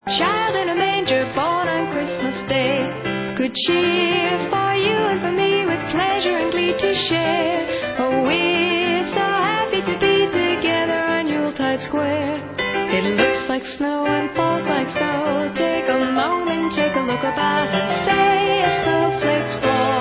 Vánoční hudba